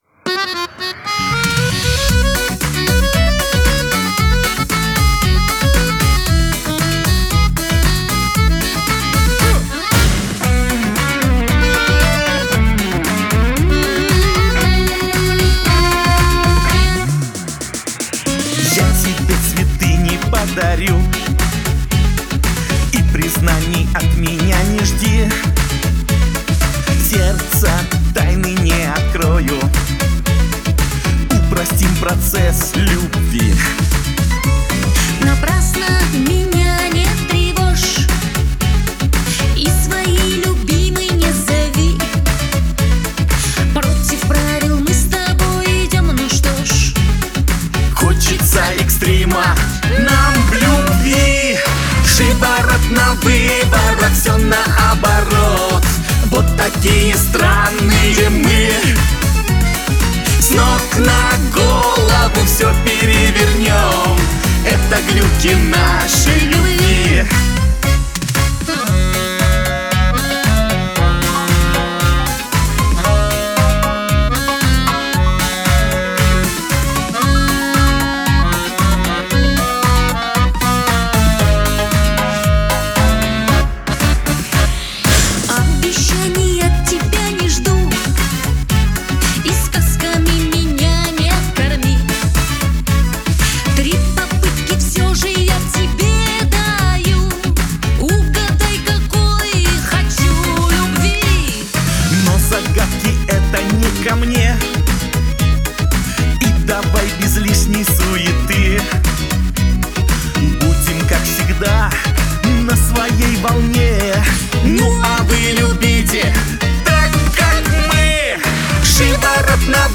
дуэт